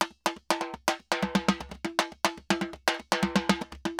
Timba_Salsa 120_2.wav